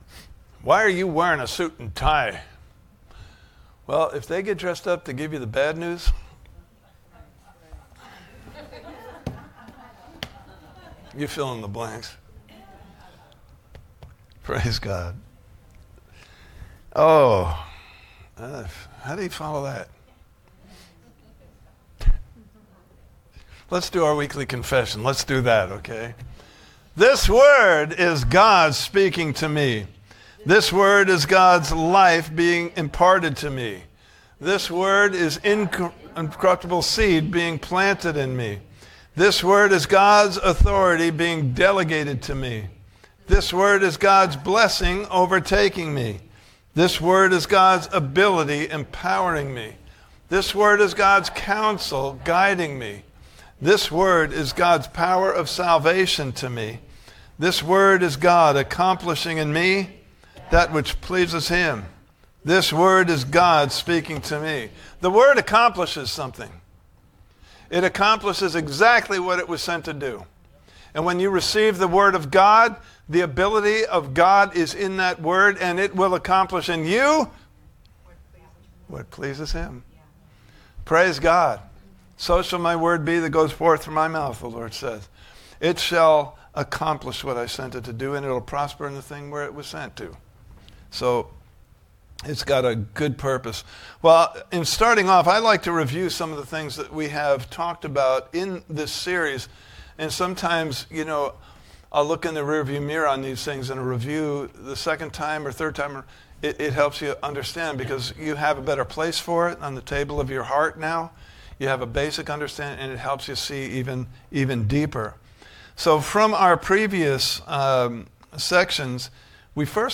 While Men Slept Service Type: Sunday Morning Service « Part 3